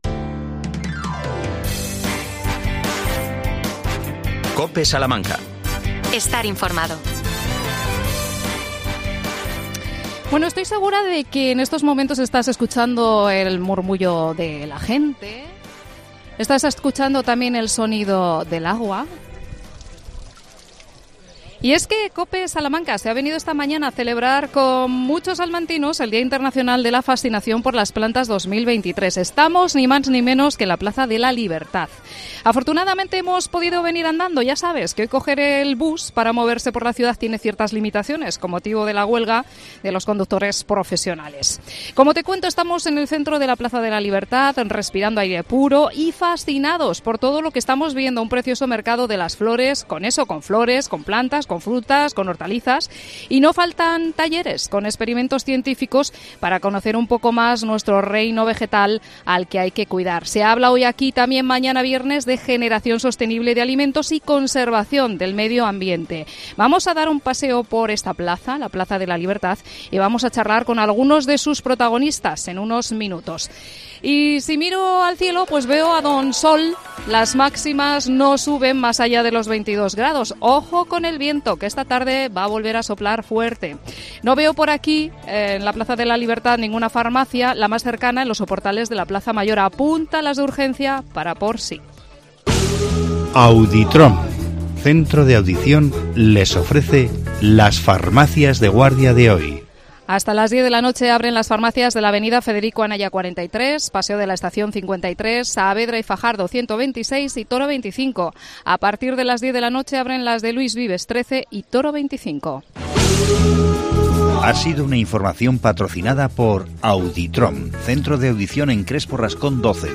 AUDIO: COPE SALAMANCA en el Mercado de las Flores de la Plaza de la Libertad.